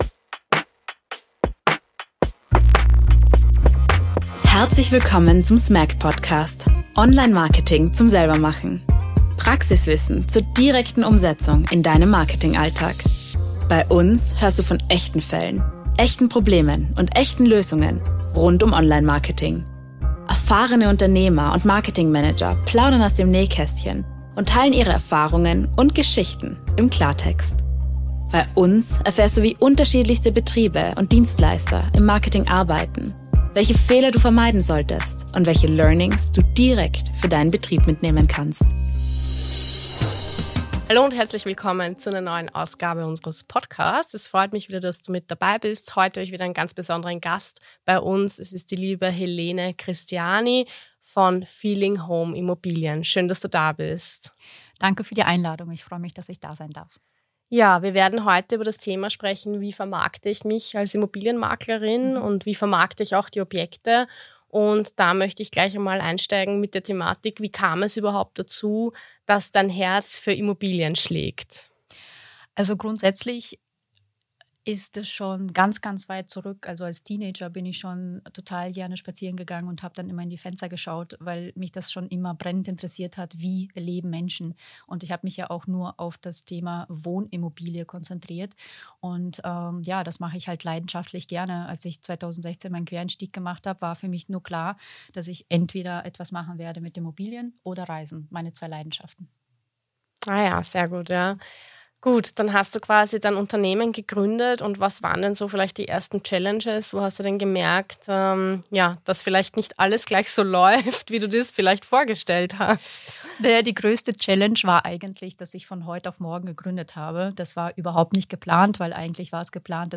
Sie teilt wertvolle Einblicke in die aktuelle Marktlage, klärt über häufige Fehler bei der Immobilienvermarktung auf und zeigt, welche Rolle Social Media, KI und Weiterentwicklung heute spielen. Ein ehrliches Gespräch über Kundennähe, Qualität – und warum Professionalität im Detail beginnt.